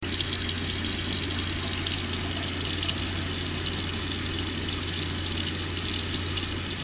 水槽水龙头
描述：打开水槽龙头，运行水，关闭水，让水从水槽中流下。